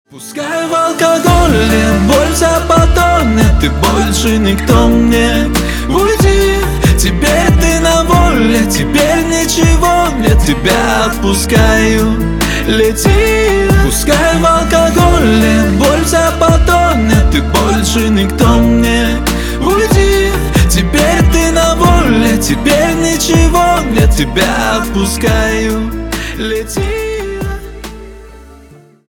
на русском на бывшую грустные